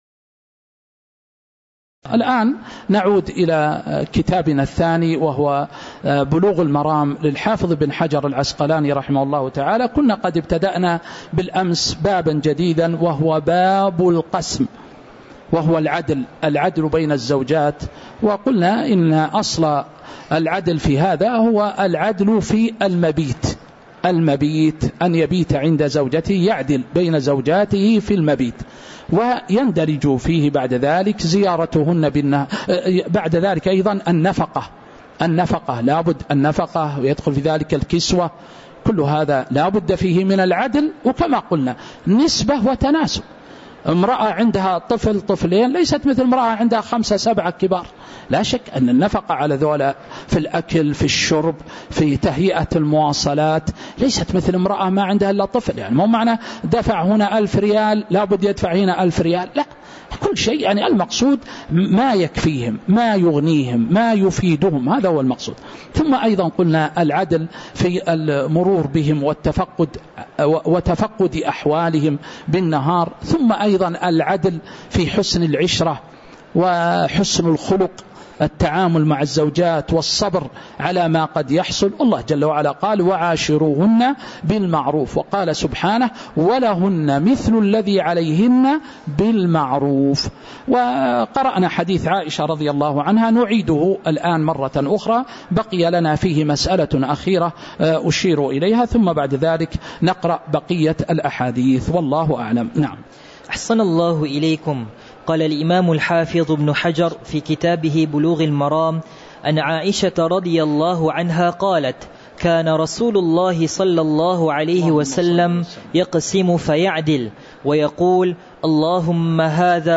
تاريخ النشر ١٥ شوال ١٤٤٦ هـ المكان: المسجد النبوي الشيخ